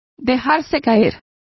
Complete with pronunciation of the translation of flops.